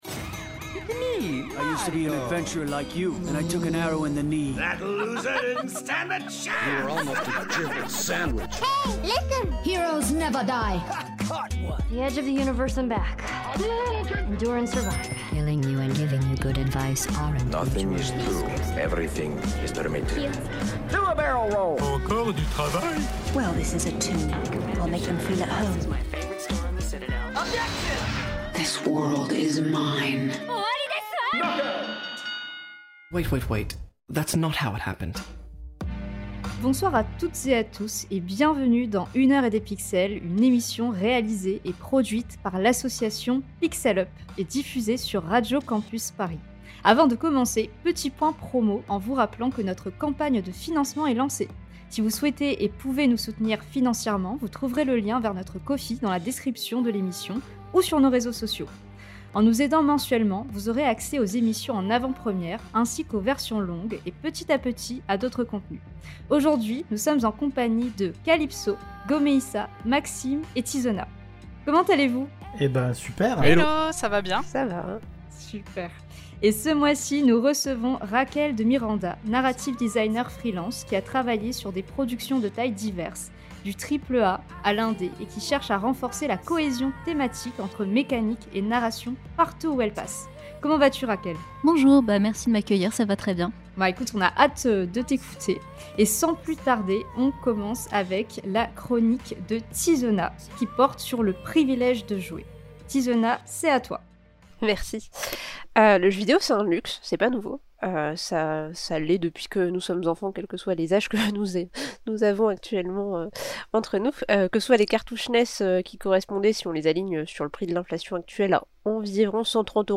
Émission diffusée le 18 avril 2026 sur Radio Campus Paris.